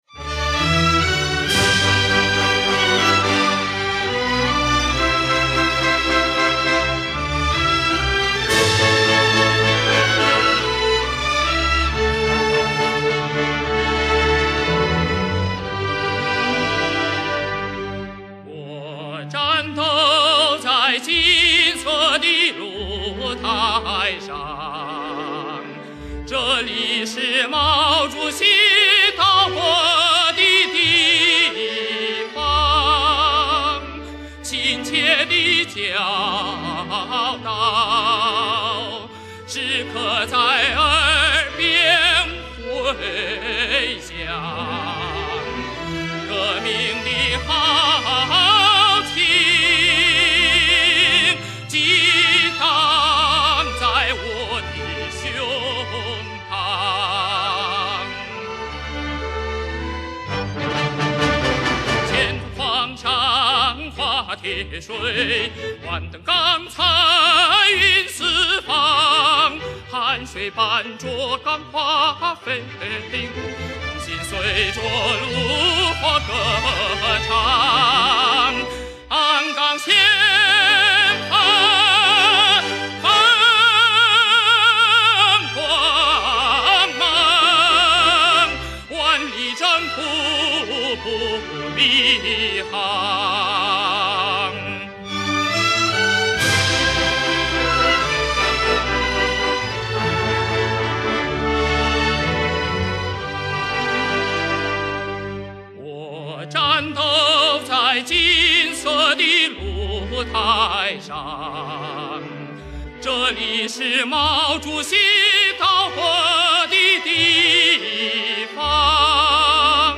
1975年录音